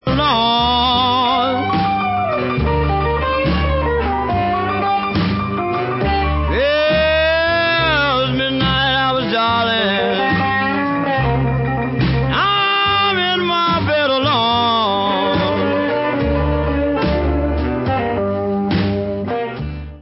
The best r&b guitarist in the world!? he did it all!!